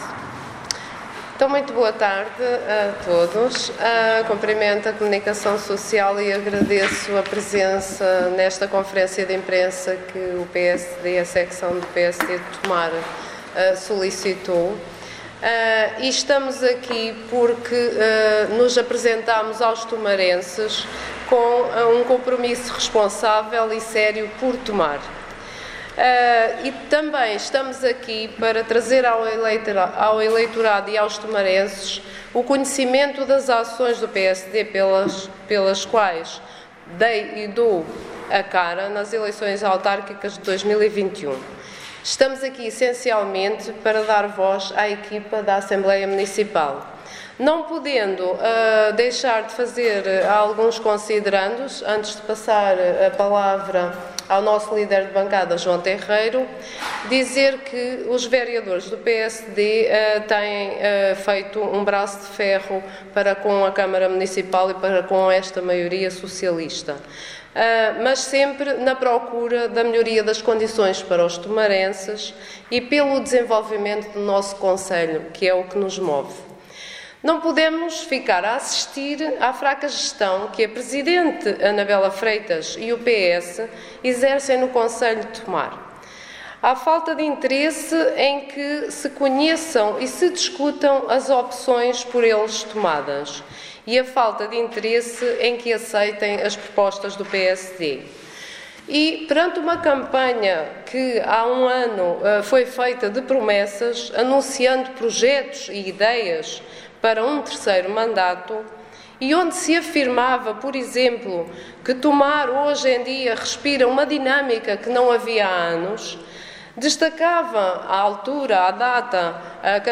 O PSD de Tomar realizou nesta segunda feira, dia 10, uma conferência de imprensa sobre a atual situação política no concelho um ano após as eleições autárquicas, com enfoque no funcionamento da assembleia municipal e na gestão da Tejo Ambiente.